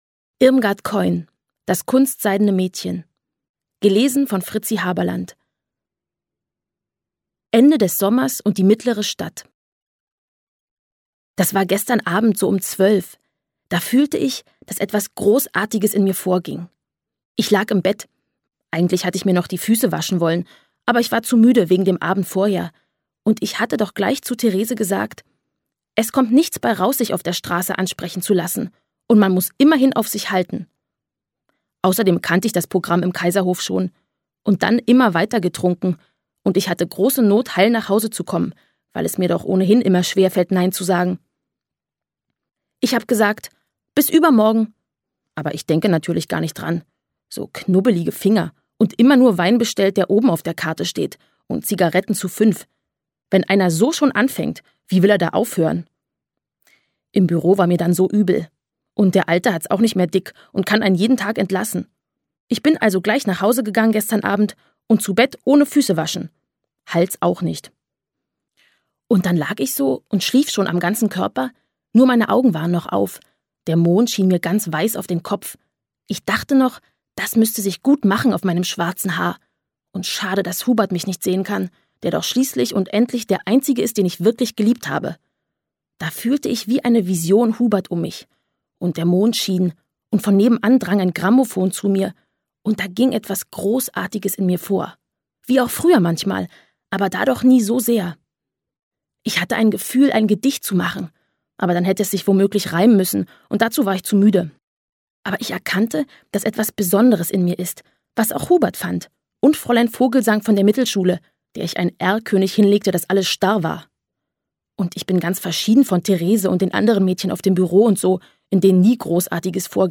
Ungekürzte Lesung mit Fritzi Haberlandt (4 CDs)
Fritzi Haberlandt (Sprecher)